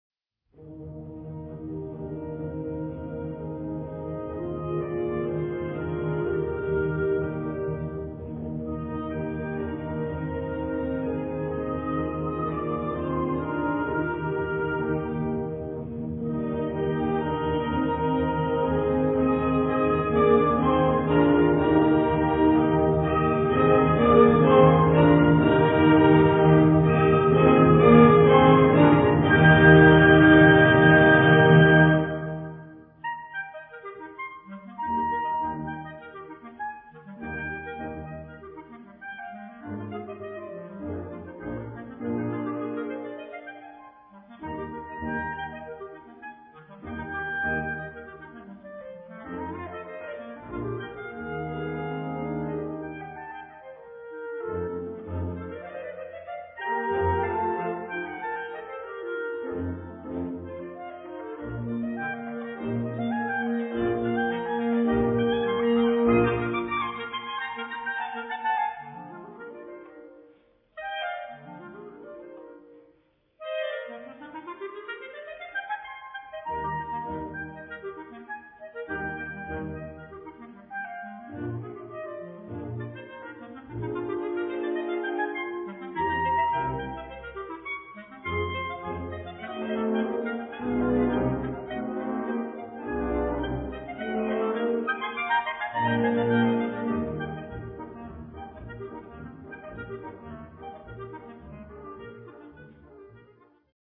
Solo für 2 Klarinetten und Blasorchester Schwierigkeit
Besetzung: Blasorchester